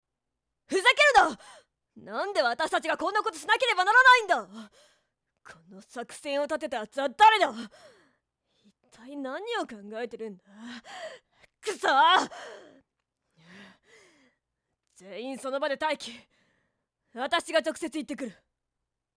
おとなし少女後輩の女の子女の先輩現実的な青年学生の男の子